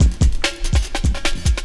16 LOOP01 -L.wav